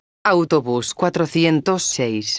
megafonias exteriores
autobus_406.wav